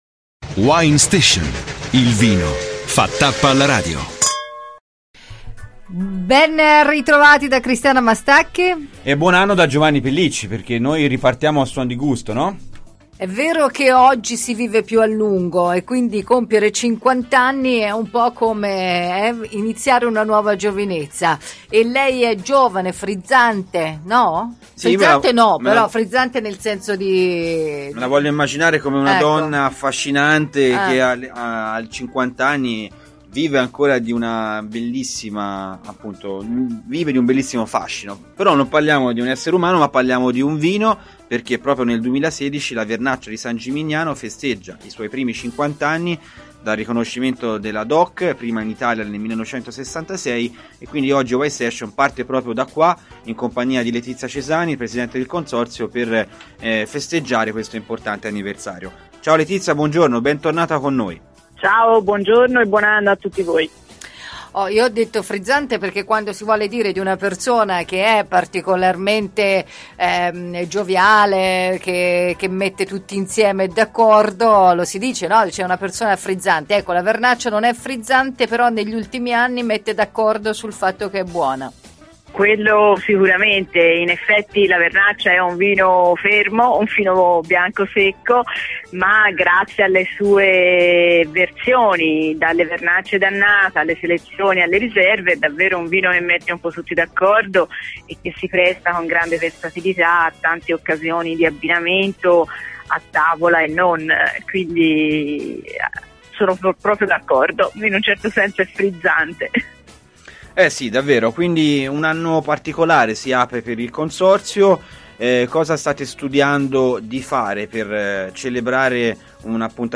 Saremo poi in giro per Firenze per mangiare il cibo di strada per antonomasia: il lampredotto .